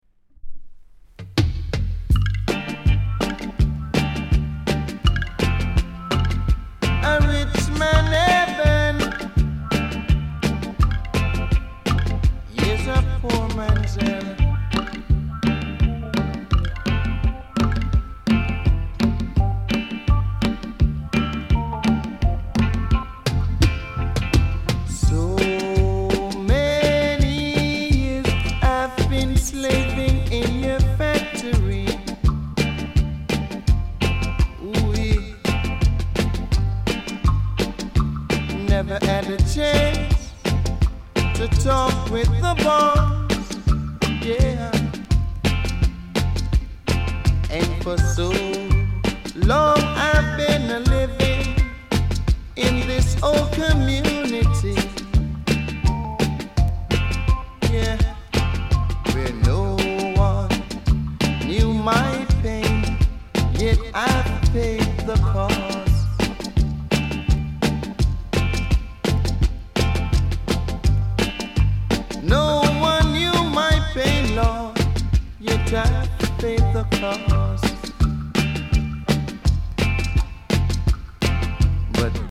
高音質